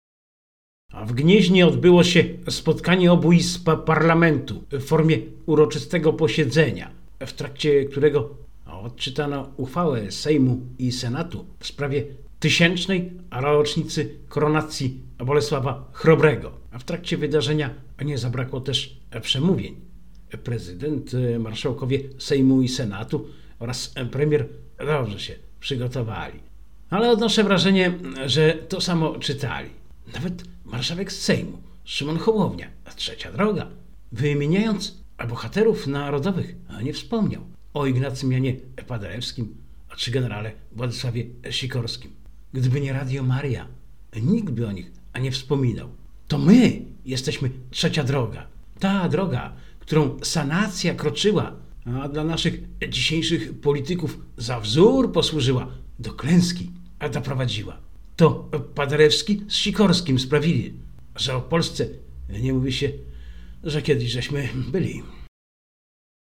Na koniec proszę, abyśmy „Bogurodzicy” posłuchali. Żołnierze na Uroczystym Posiedzeniu Sejmu i Senatu w Gnieźnie ją zaśpiewali.
Tysiąclecie koronacji Bolesława Chrobrego żeśmy świętowali.